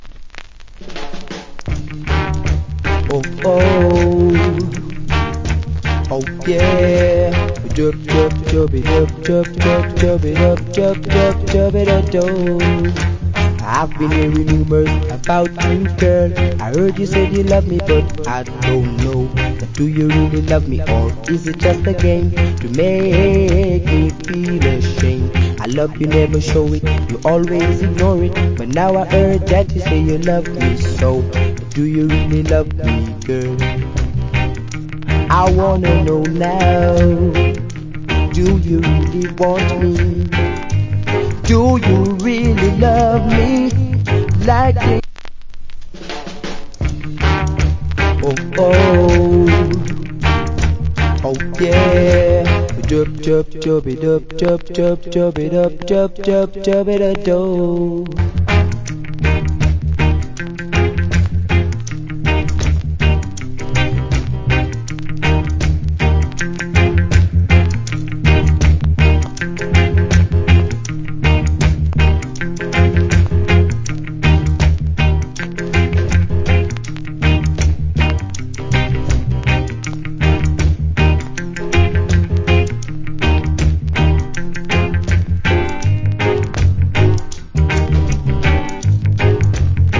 Nice Reggae Vocal.